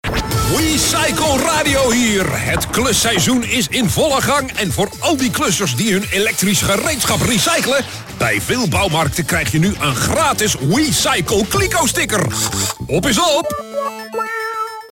Radiocommercial